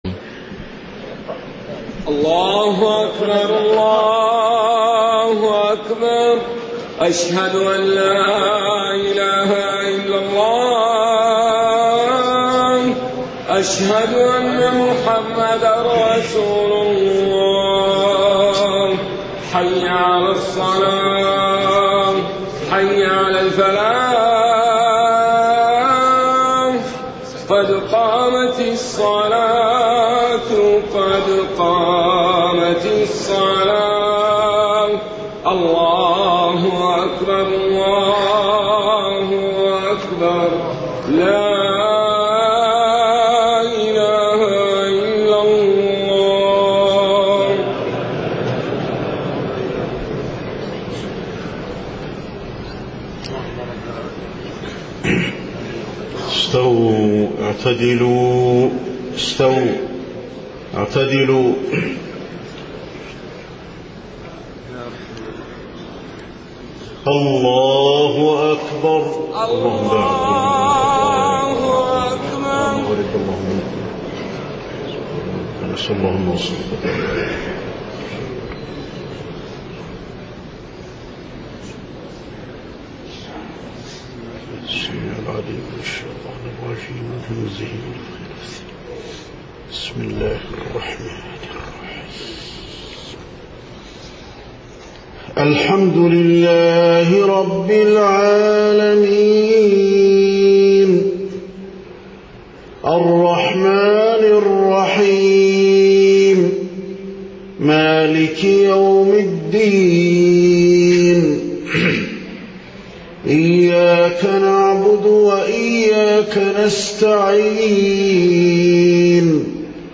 صلاة العشاء 3 صفر 1430هـ من سورة الأحزاب 56-71 > 1430 🕌 > الفروض - تلاوات الحرمين